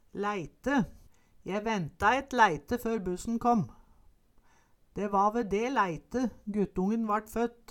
Høyr på uttala Ordklasse: Substantiv inkjekjønn Kategori: Tida (dagen, året, merkedagar) Attende til søk